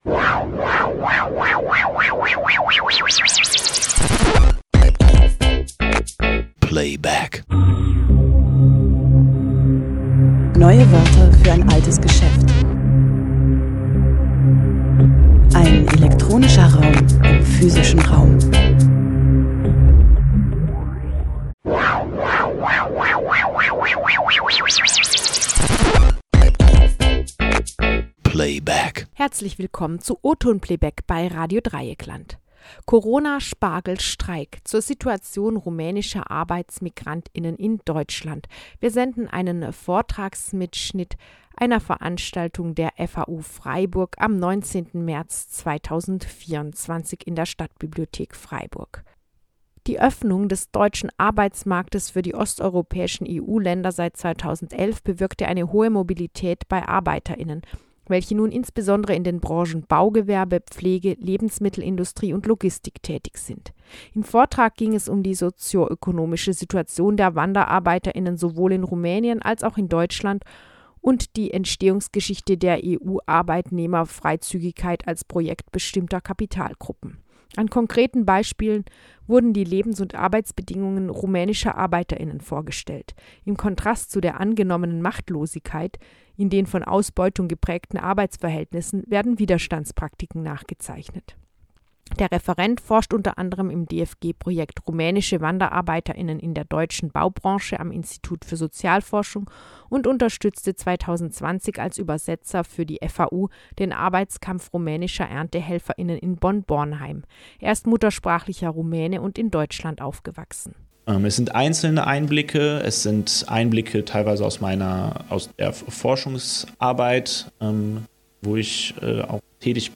Am Abend des 18. März im KoKi Freiburg.